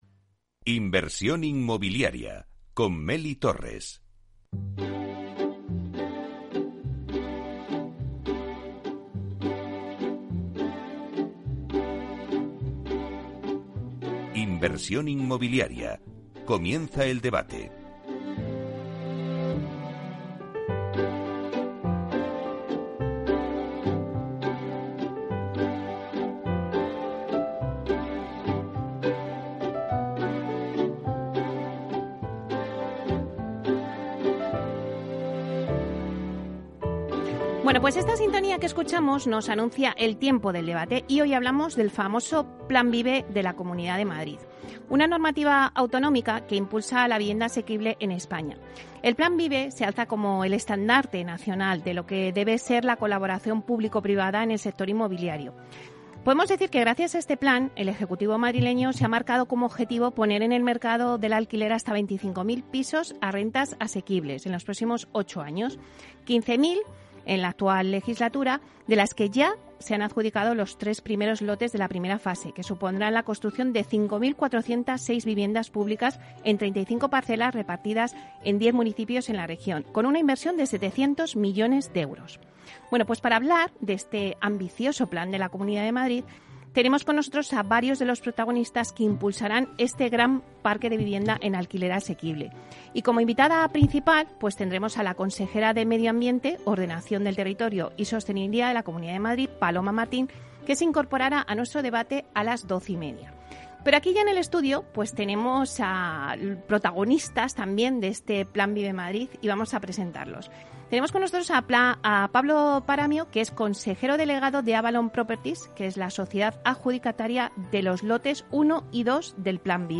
II Debate sobre el Plan VIVE de la Comunidad de Madrid